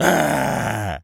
gorilla_angry_07.wav